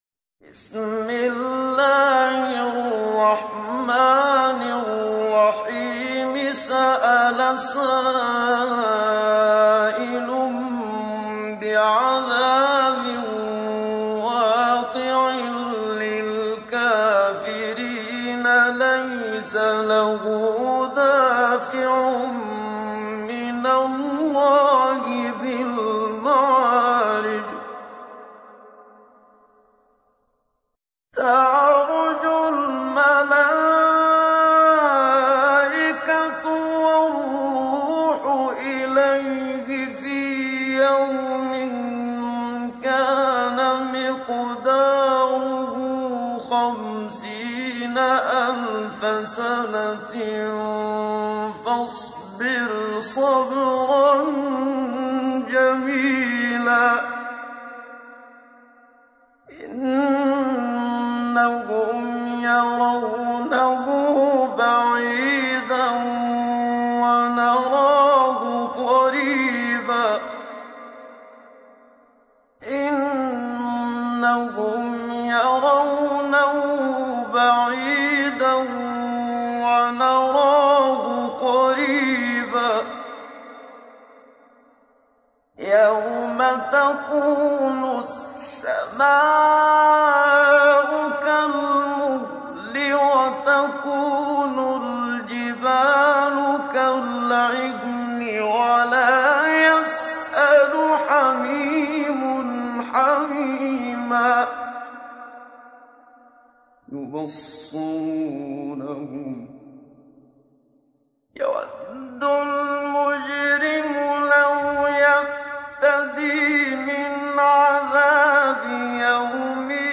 تحميل : 70. سورة المعارج / القارئ محمد صديق المنشاوي / القرآن الكريم / موقع يا حسين